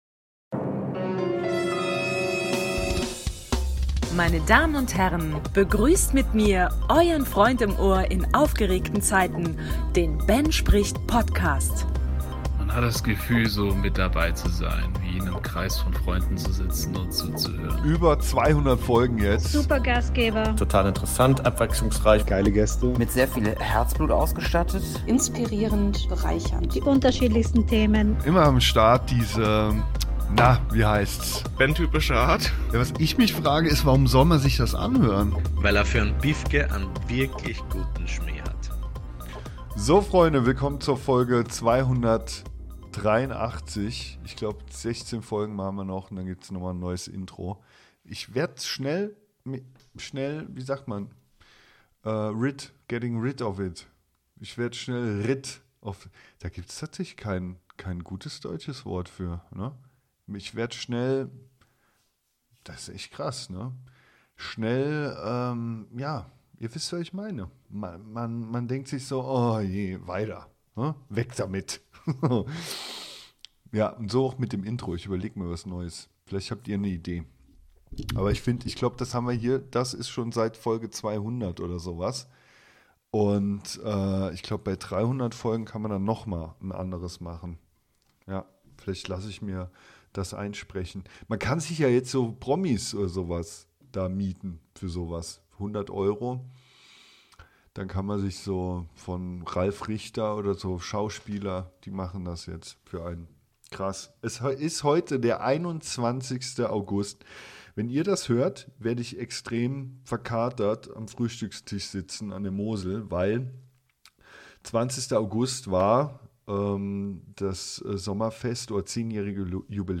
Beschreibung vor 3 Jahren Folge 283: Mein Leben als 500jähriger (solo) Ich spreche darüber ob ich lieber 80, 500 oder 1000 Jahre werden würde und warum. Hörerbeiträge dazu lese ich auch vor. Außerdem habe ich innerhalb weniger Tage eine extrem asoziale und eine extrem berührende Situation im Einzelhandel beobachtet.